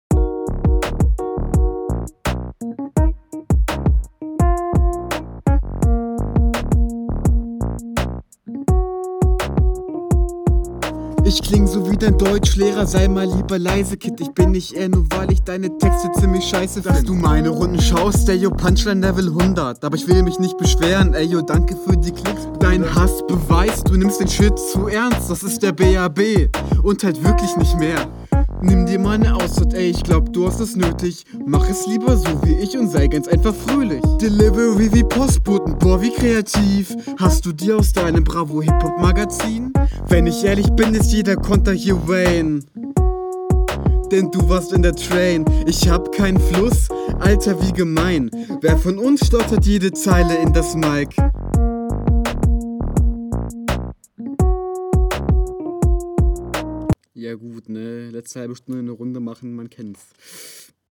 Als erstes fällt mir beim Anhören der Unterschied in der Soundqualität zum Gegner auf.